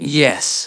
ovos-tts-plugin-deepponies_Adachi Tohru_en.wav